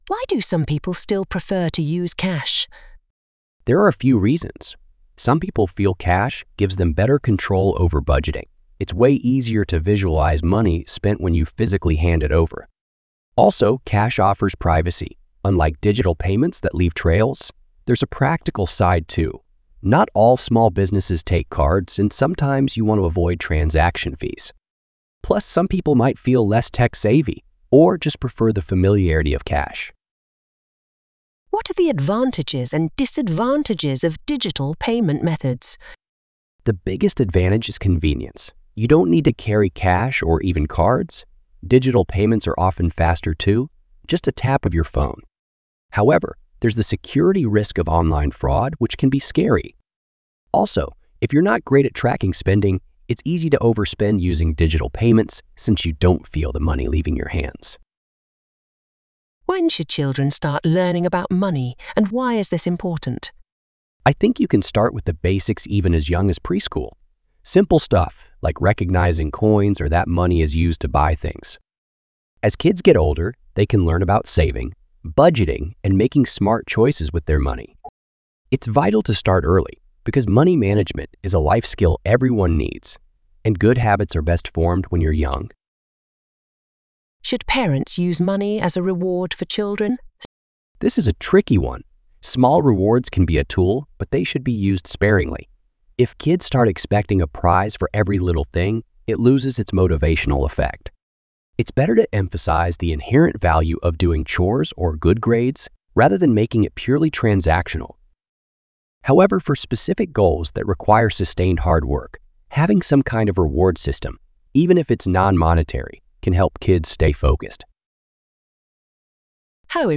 Trong bài viết này, Mc IELTS chia sẻ câu trả lời mẫu band 8.0+ từ cựu giám khảo IELTS, kèm theo các câu hỏi mở rộng và bản audio từ giáo viên bản xứ để bạn luyện phát âm, ngữ điệu và tốc độ nói tự nhiên.